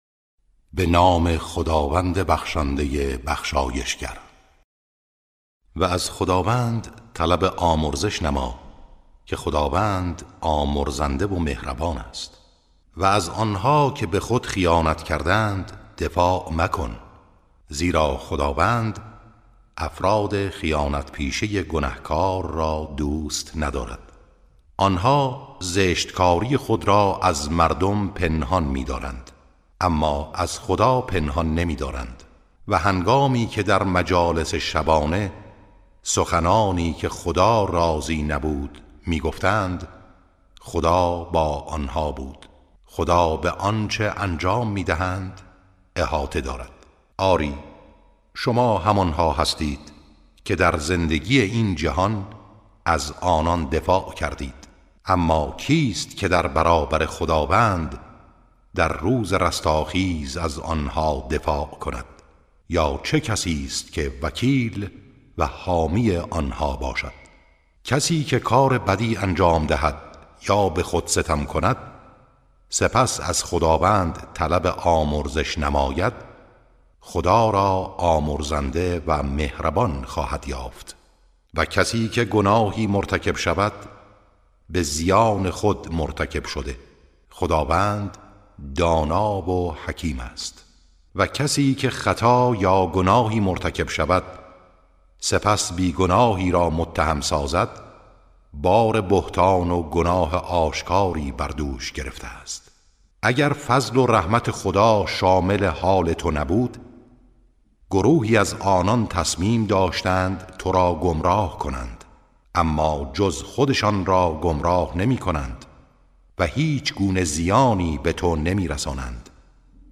ترتیل صفحه ۹۶ سوره مبارکه نساء(جزء پنجم)
ترتیل سوره(نساء)